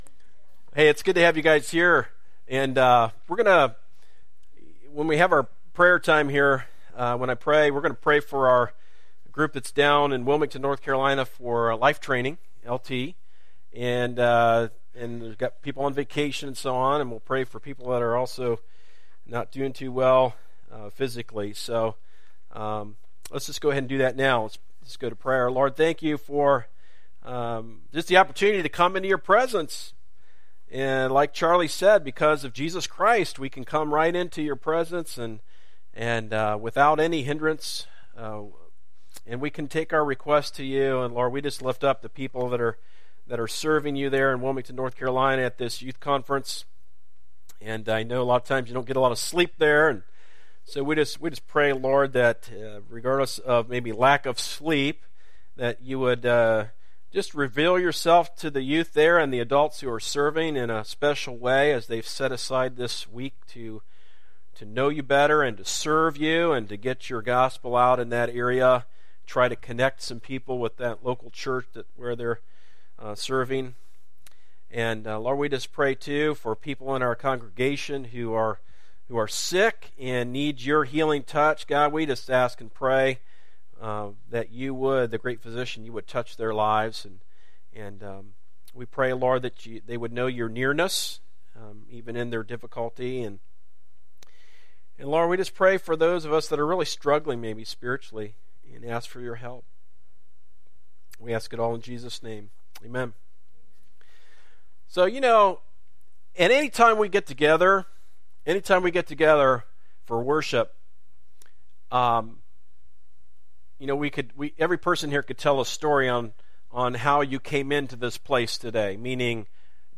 A message from the series "Heart Matters."